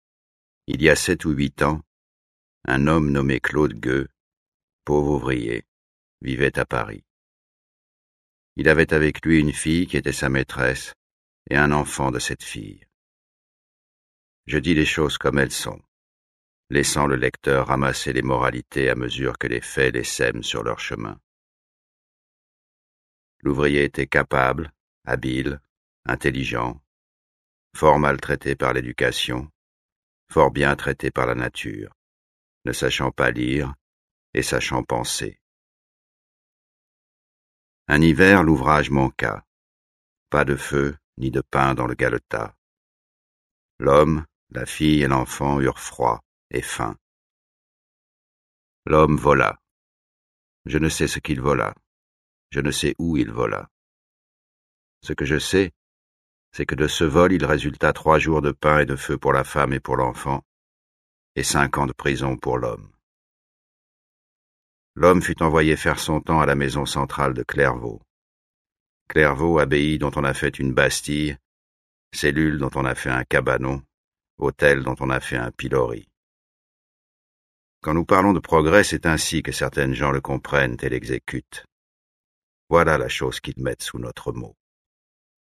je découvre un extrait - Claude Gueux de Victor Hugo
Jean-Claude Dauphin, issu d’une belle lignée de comédiens, est une des grandes figures du cinéma français.
Robert Badinter , garde des Sceaux entre 1981 et 1986, auteur des récits L'Exécution et L'Abolition , a obtenu l'abolition de la peine de mort en France en septembre 1981, soit plus d'un siècle après le discours prononcé par Victor Hugo auquel il prête ici sa voix.